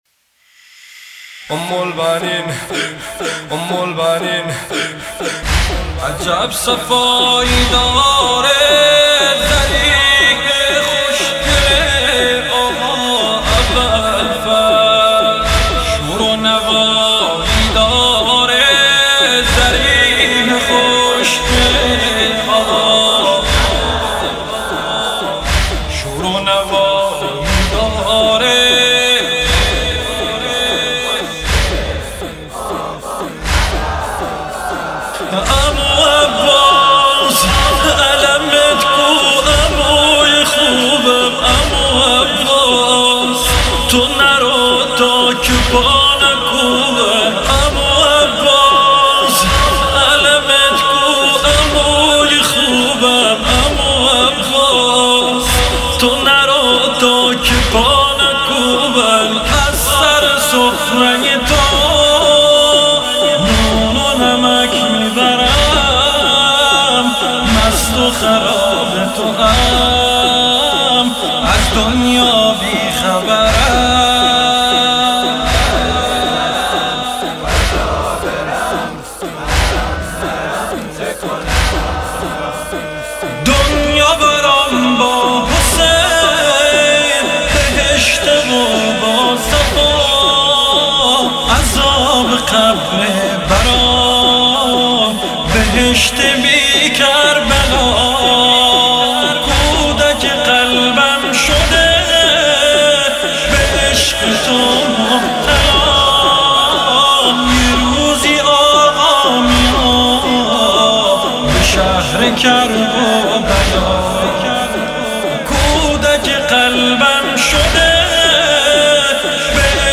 دانلود مداحی با کیفیت 320